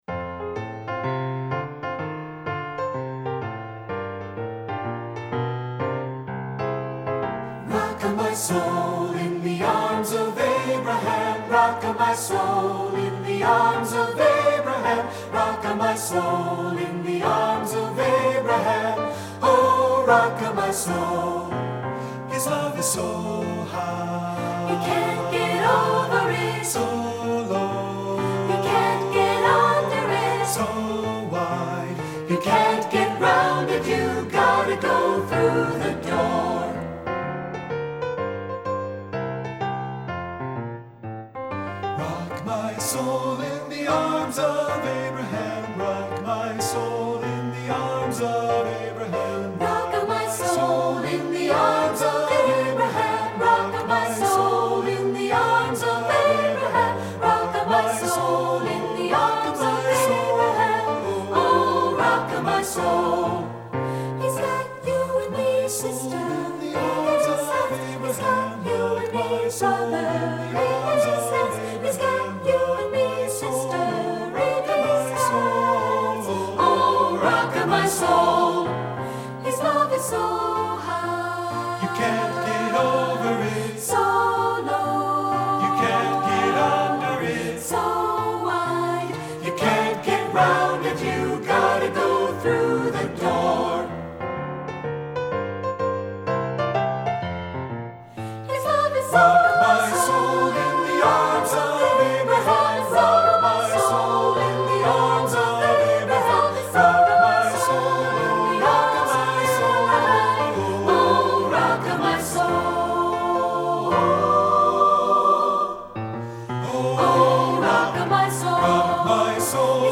African-American Spiritual Arranger
Voicing: SAB